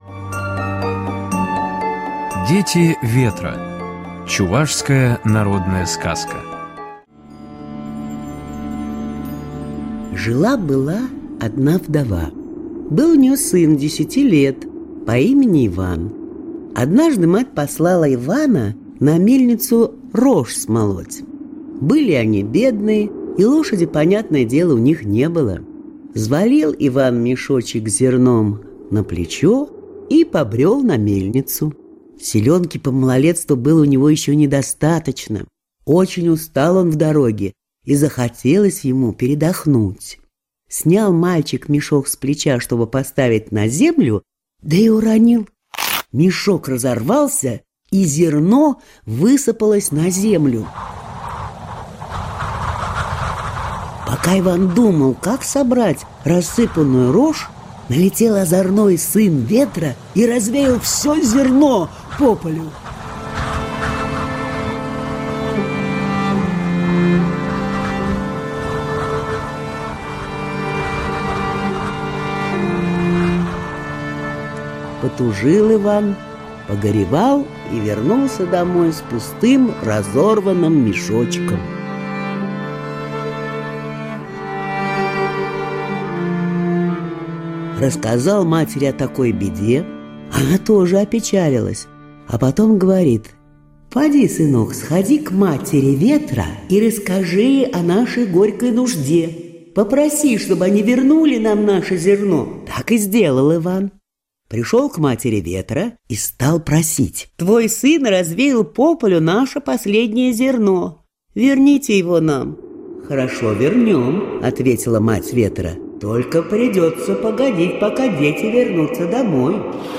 Дети ветра - чувашская аудиосказка - слушать онлайн